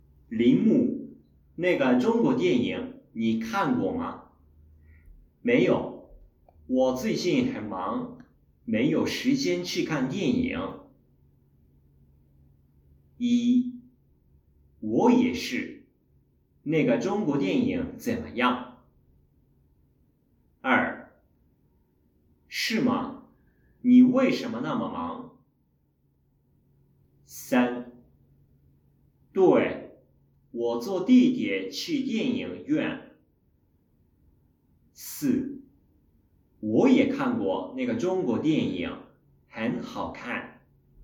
2人の中国語の対話を聞いて、それに続く言葉として最も適当なものを中国語で読まれる4つの選択肢から選ぶ問題(5問)
全ての問題は1題ごとに2回ずつ放送されます。